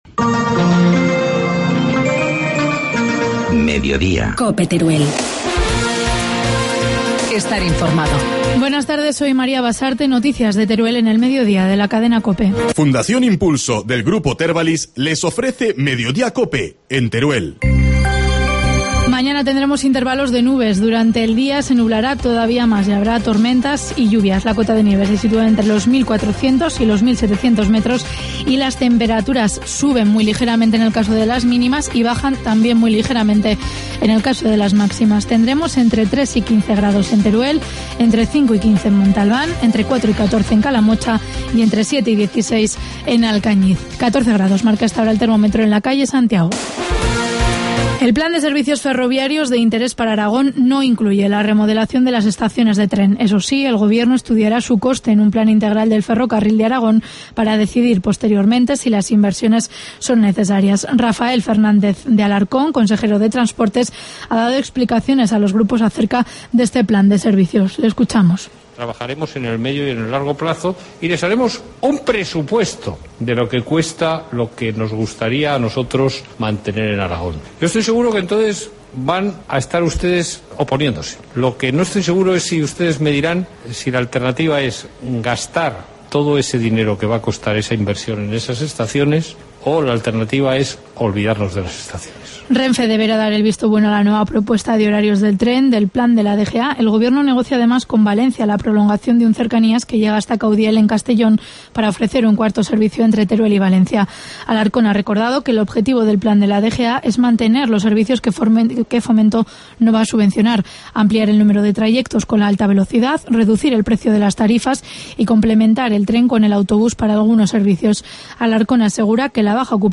Informativo mediodía, jueves 16 de mayo